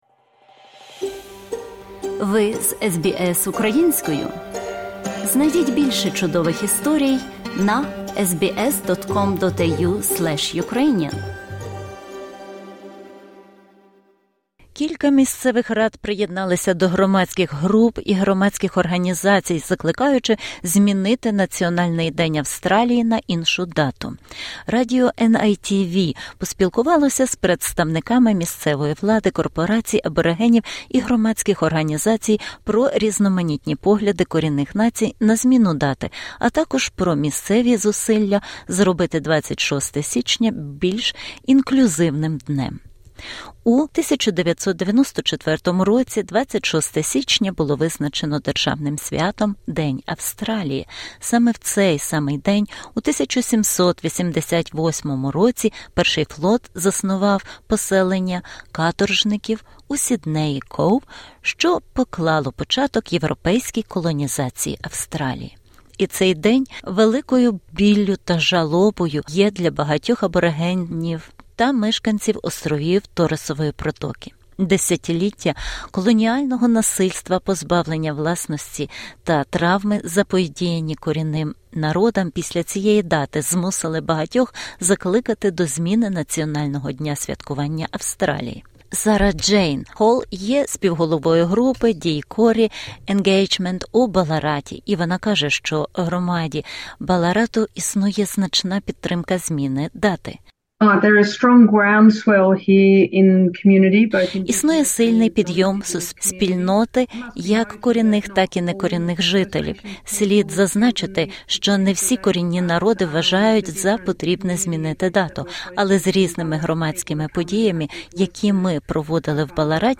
A number of local councils have joined community groups and grass-roots organisations in calling for Australia's national day of celebration to be changed to a different date. NITV Radio spoke to representatives from local government, Aboriginal Corporations and community organisations about diverse First Nations perspectives around changing the date, as well as local efforts to make January 26 a more inclusive day.